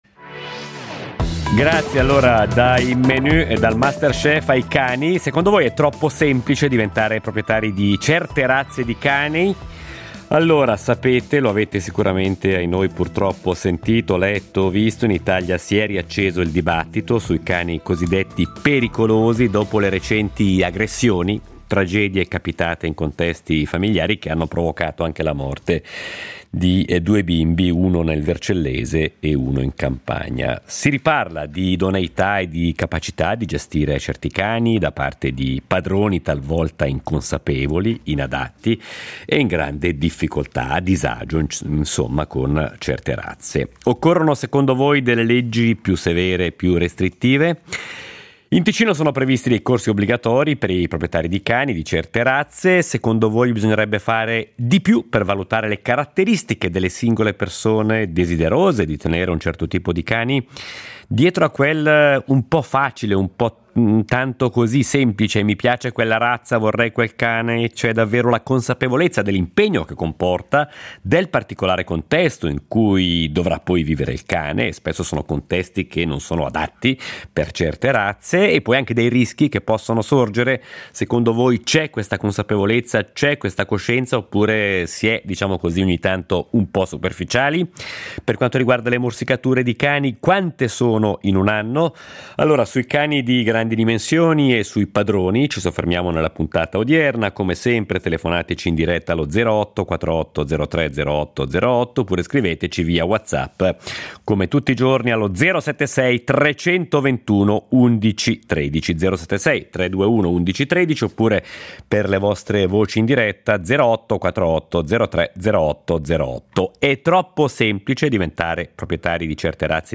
È ospite: Luca Bacciarini , veterinario cantonale